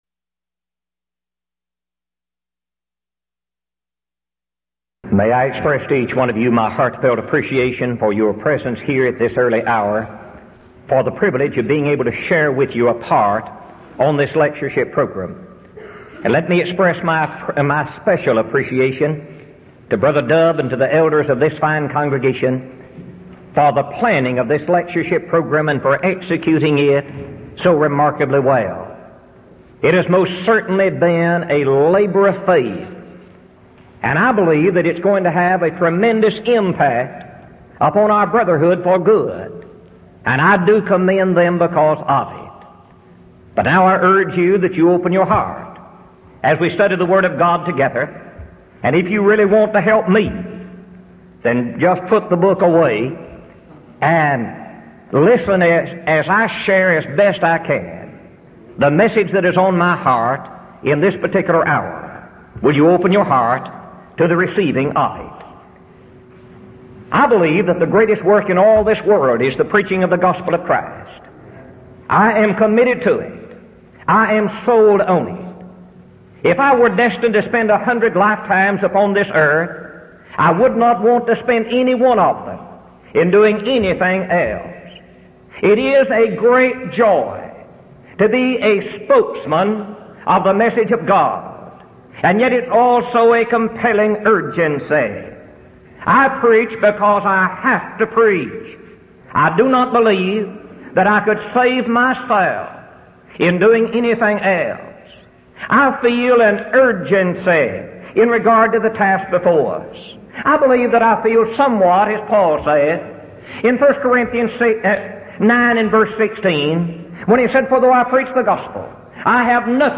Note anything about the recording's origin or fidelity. Event: 1982 Denton Lectures Theme/Title: Studies in 1 Corinthians